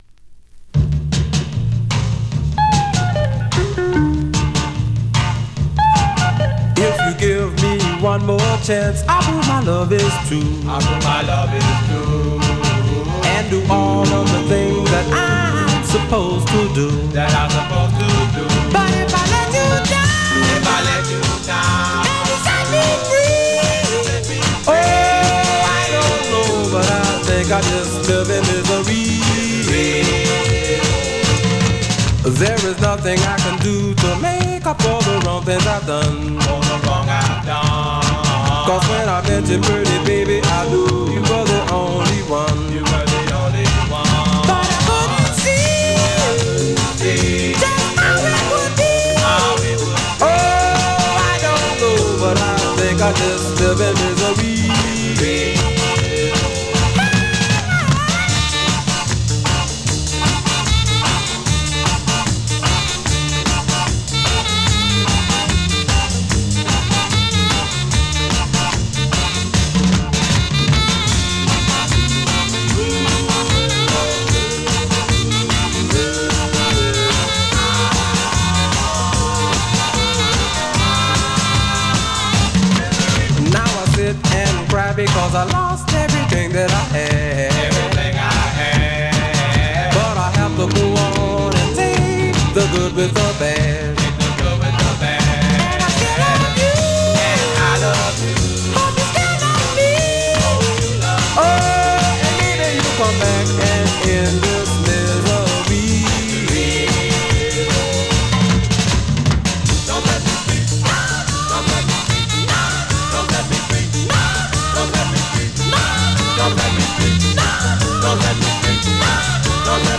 Vinyl has a few light marks plays great .
Great up-tempo Northern / Rnb dancer